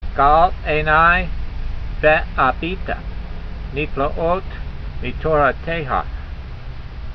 v18_voice.mp3